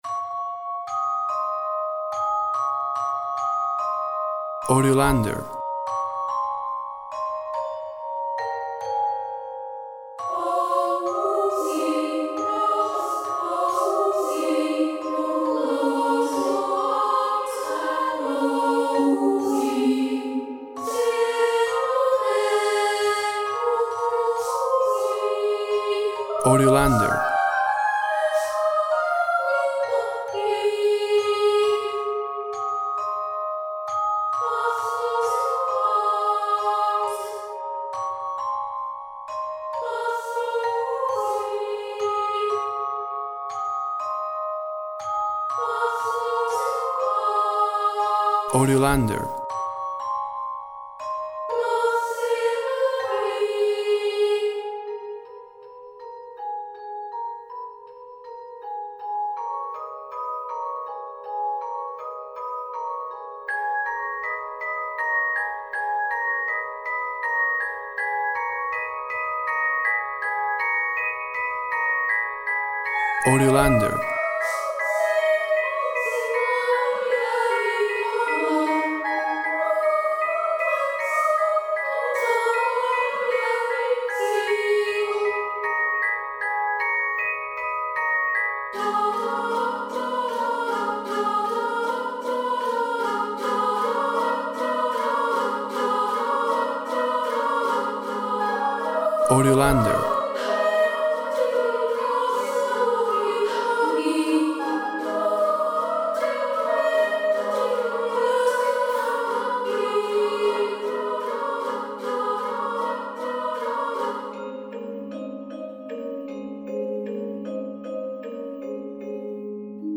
Tempo (BPM) 120/100